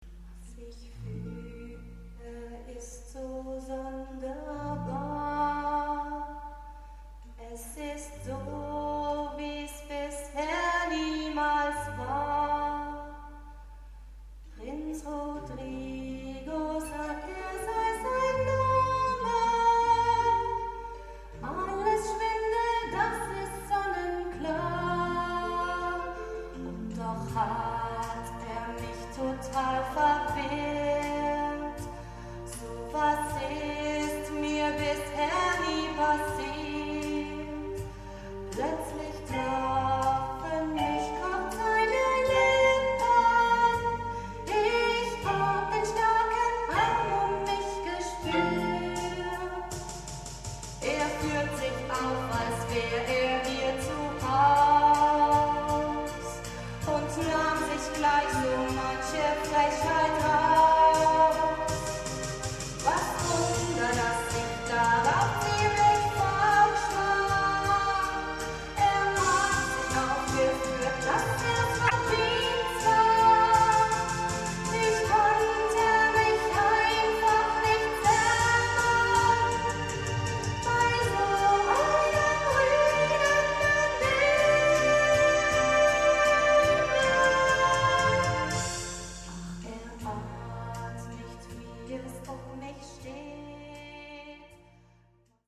Musical mit Anspruch, romantisch, schwungvoll, abwechslungsreich